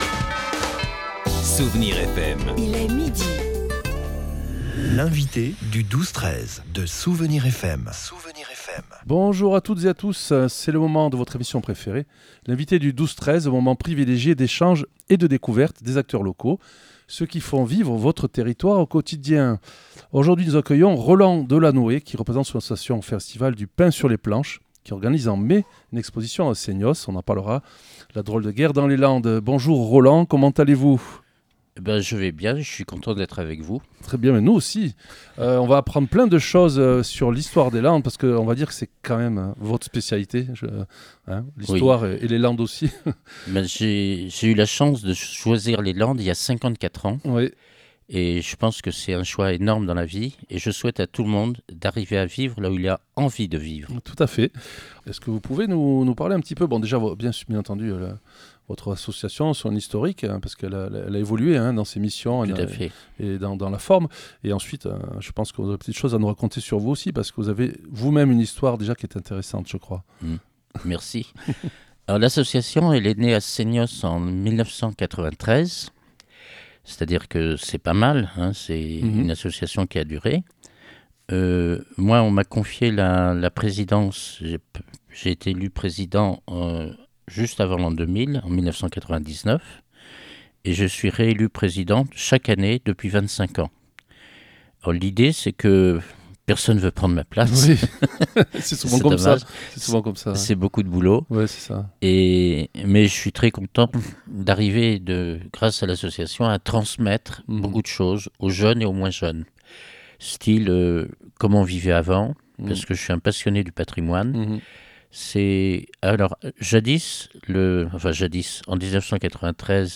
Vous l'entendrez raconter des tas d'anecdotes, des petites histoires qui fond la grande histoire comme les petits ruisseaux font les grandes rivières !